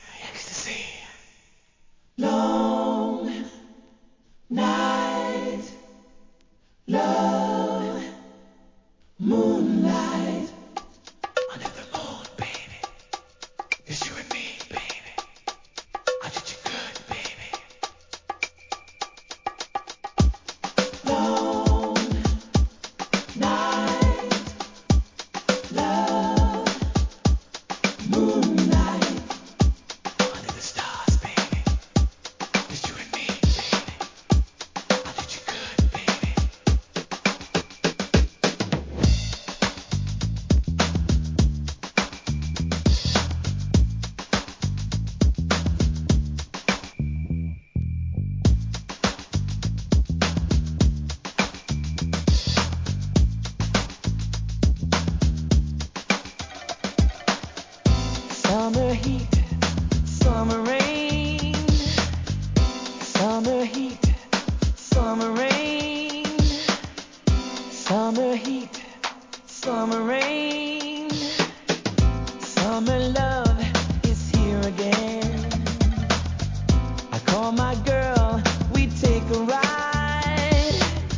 HIP HOP/R&B
アダルティー作品♪